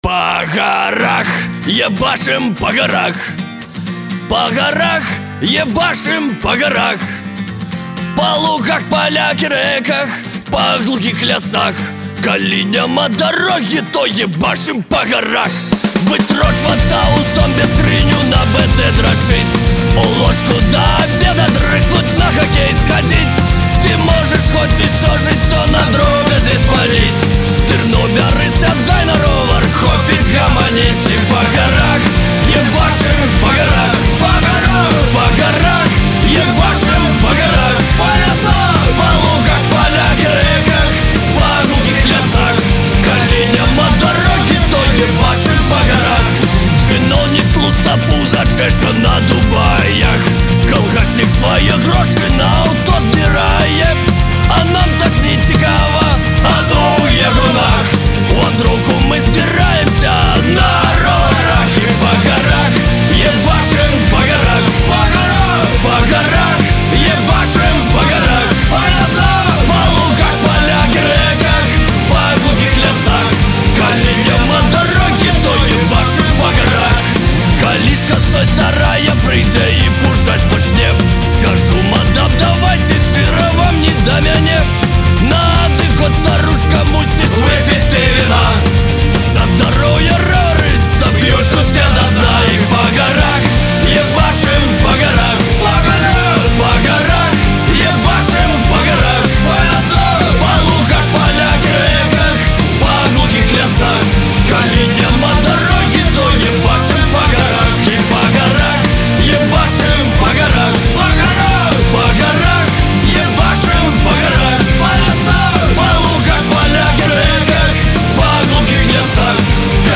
Дебютный альбом гродненской фолк-панк-команды